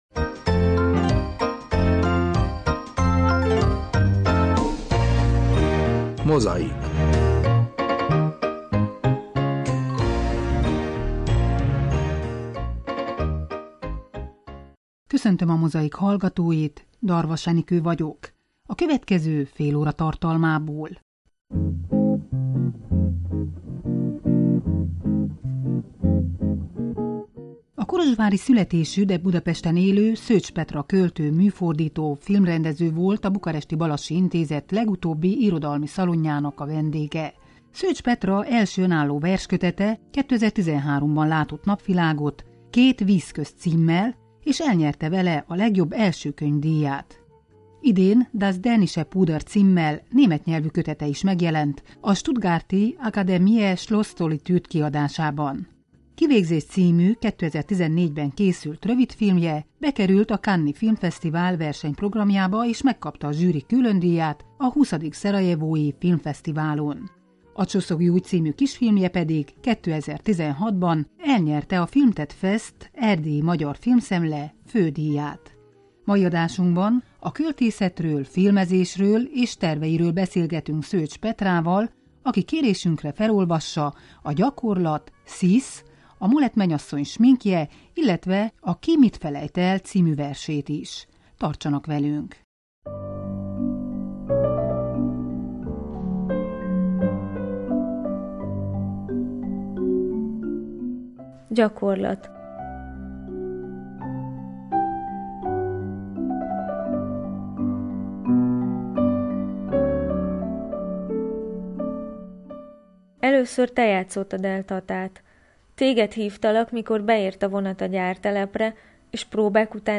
Beszélgetés